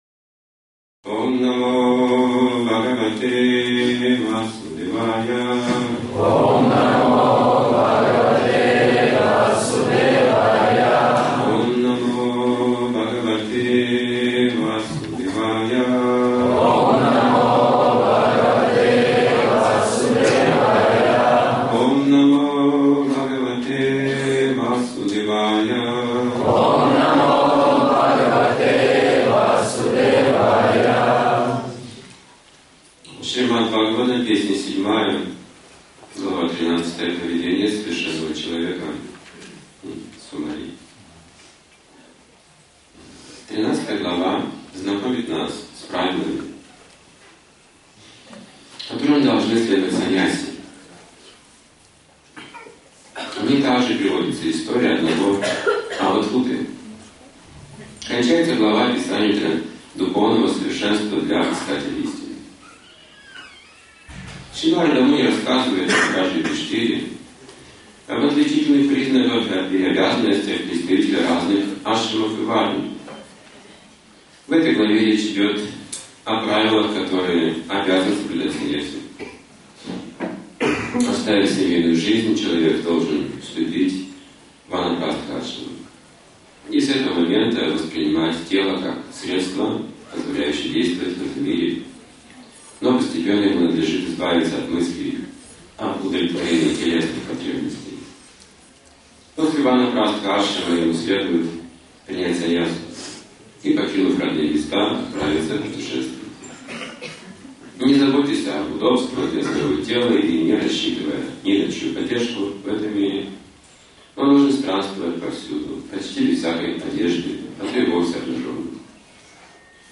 Вопросы после лекции